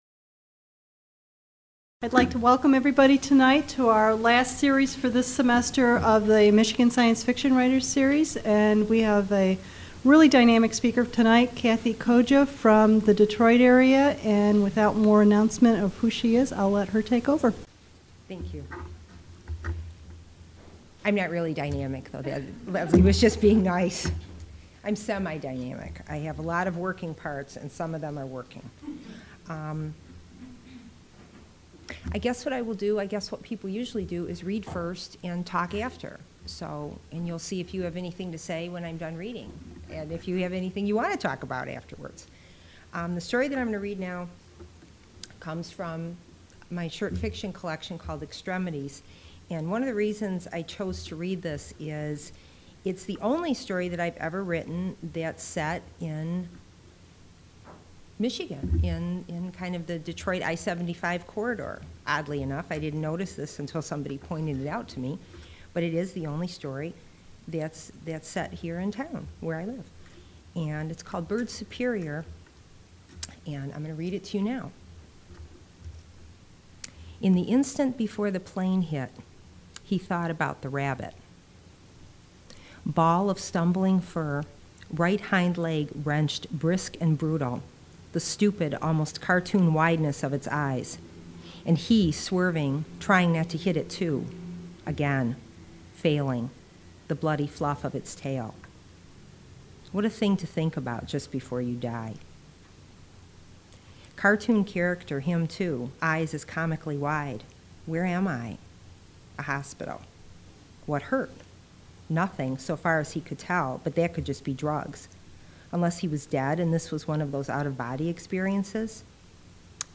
at the Michigan Writers Series